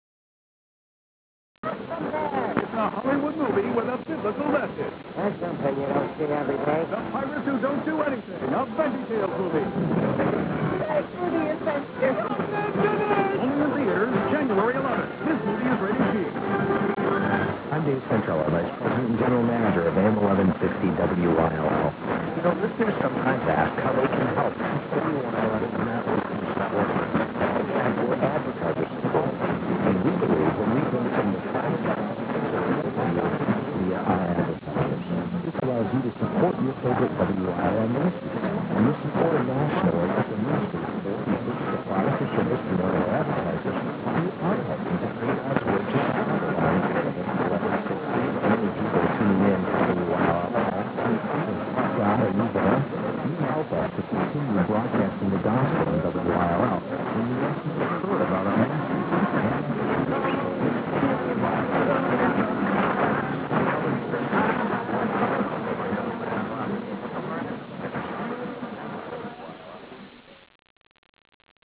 FOREIGN DX CLIPS